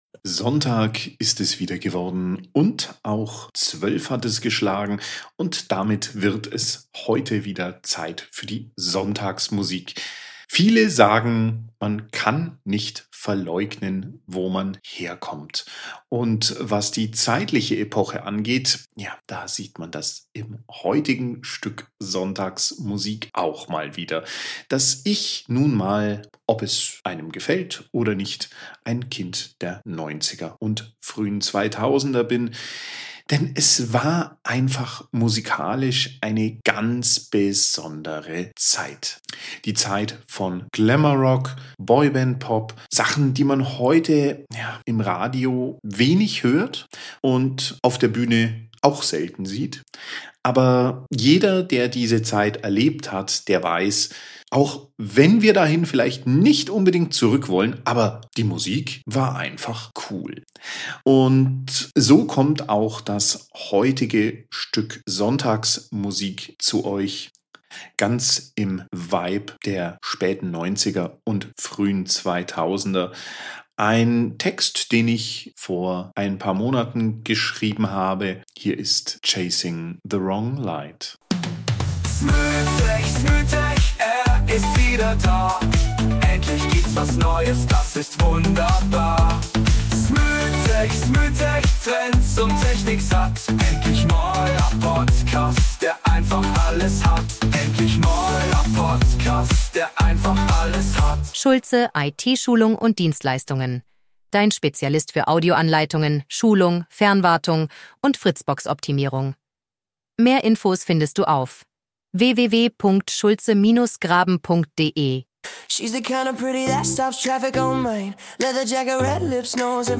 Back to the 90s
**90er Boyband Vibes meets moderner Herzschmerz mit Happy End** –
catchy Refrain, der sofort im Kopf bleibt: „I’m not chasing
- 90er und 2000er Musik
- Boyband-Pop Revival
- Glamorock